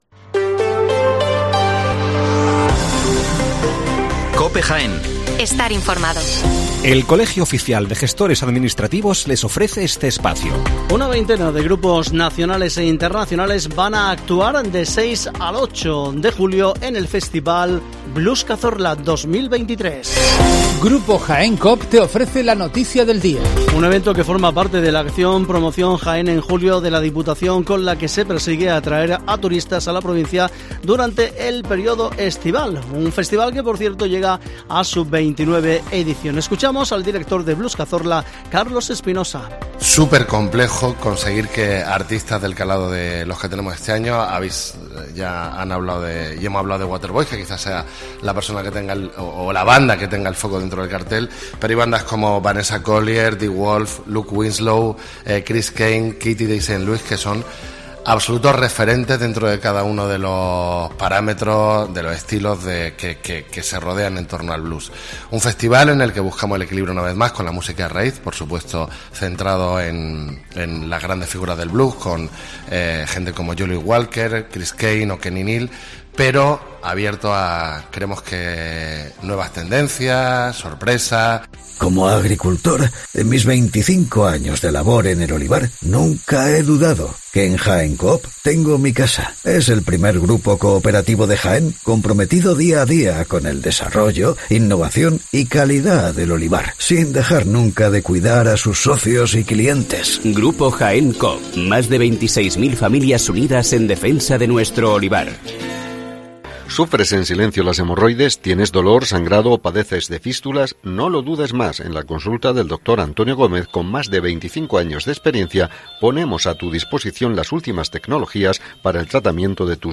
Las noticias matinales en Herrera en COPE 7:55 horas